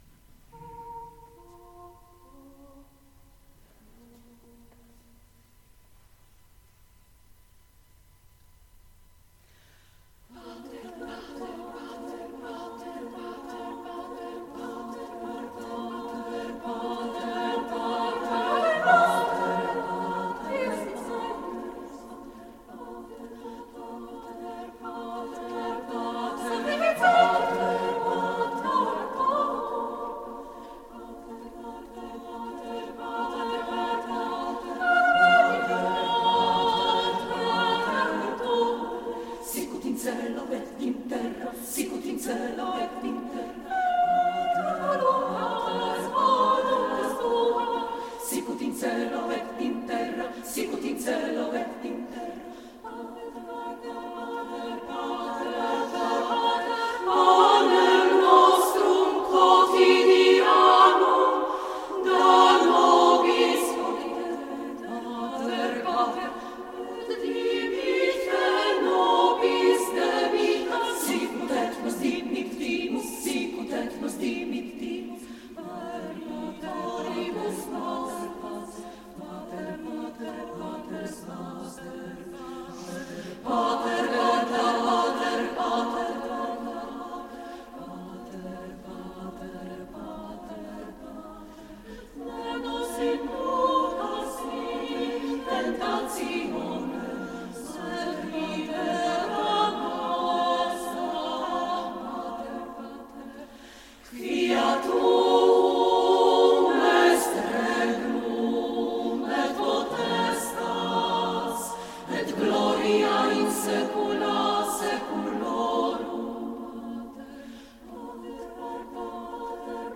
Wydarzenie miało miejsce 3 czerwca w zabytkowej świątyni parafialnej pw. Trójcy Przenajświętszej w Tykocinie.
Grand Prix w tym roku trafiło do chóru kameralnego Sirenes z Warszawy.
Sirenes-Pater-Noster.mp3